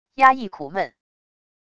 压抑苦闷wav音频